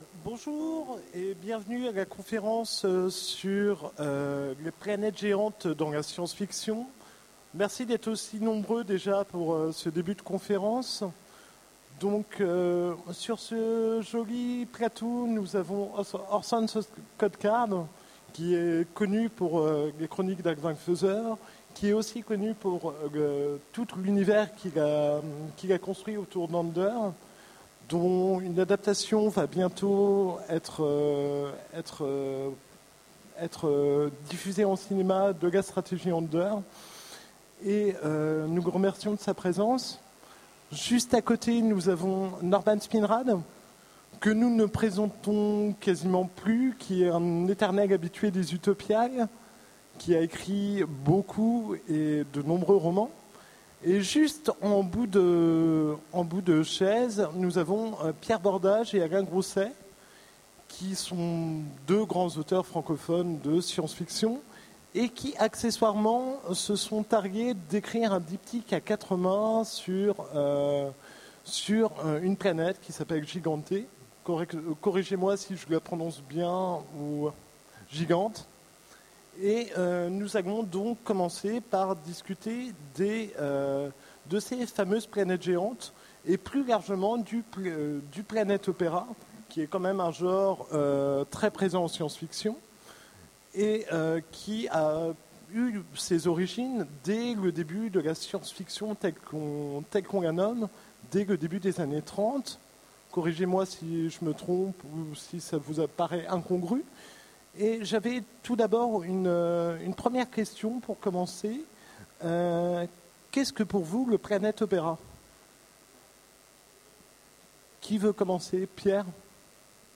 Utopiales 13 : Conférence Les planètes géantes dans la science-fiction
Conférence